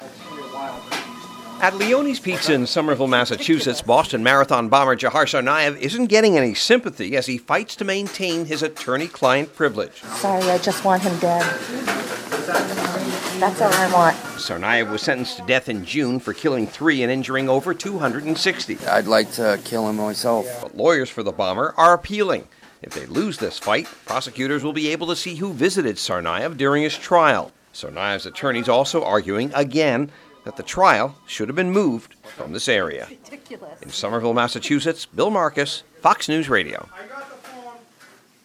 A SOMERVILLE, MASSACHUSETTS PIZZA SHOP PATRON HAS NO SYMPATHY FOR BOSTON MARATHON BOMBER DZHOKHAR TSARNAEV.